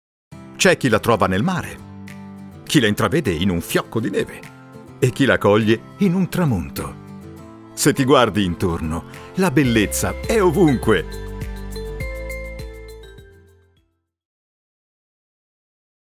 happy-allegro-naturale-semplice.mp3